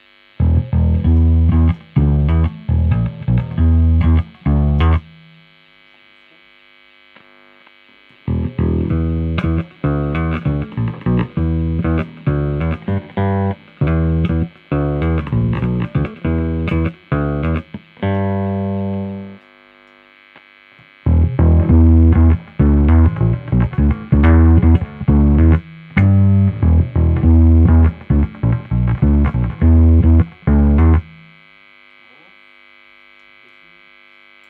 cet ampli tiens les graves de facon très impressionnate donc j'y ai collé un coup de basse squier précision micros fender US et ca marche bien sur mon cab basse 1x15!
et a la basse !
y a pas a dire il passe très très bien....
basse.mp3